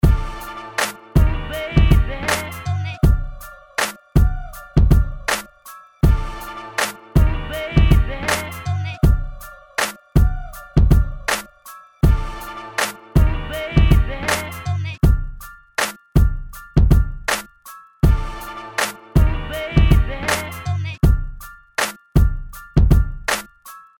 East Coast Rap Beats